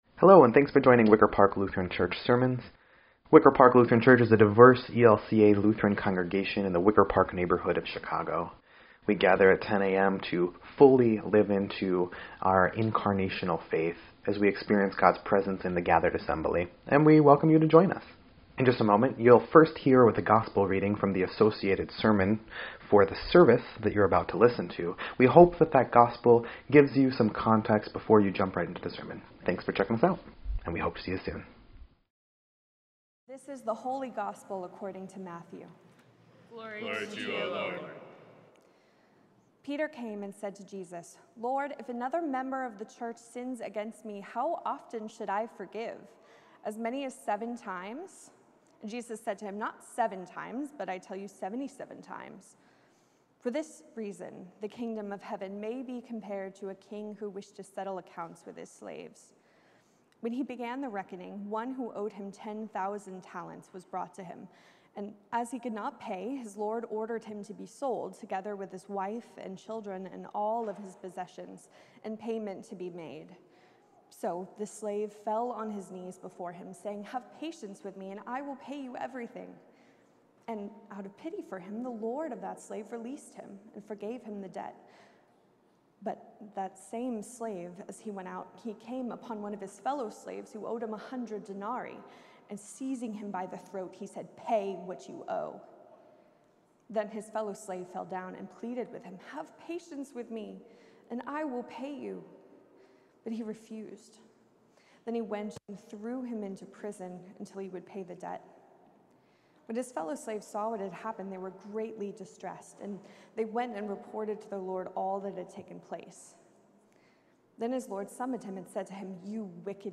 Sermon-9.17.23_EDIT.mp3